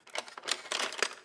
Babushka / audio / sfx / Kitchen / SFX_Cutlery_03.wav
SFX_Cutlery_03.wav